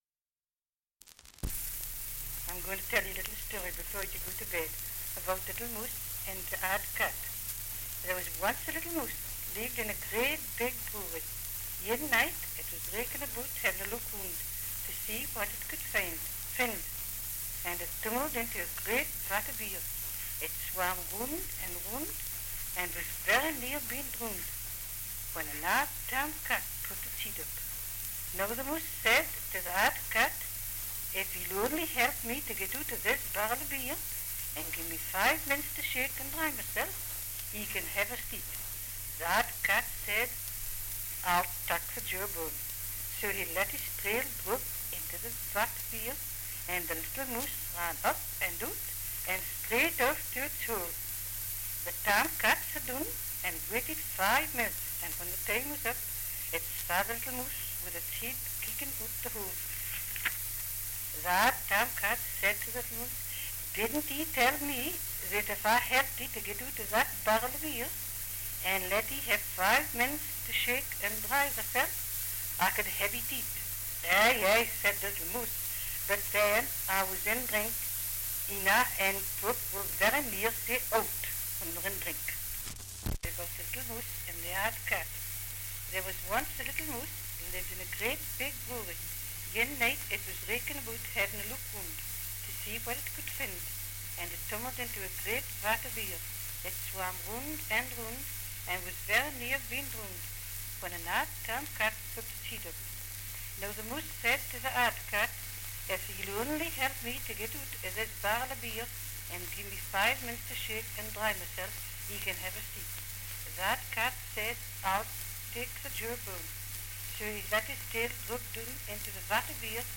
Dialect recording in Northumberland
78 r.p.m., cellulose nitrate on aluminium
English Language - Dialects